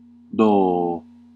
Ääntäminen
US : IPA : [sə.ˈrɛn.dɜː]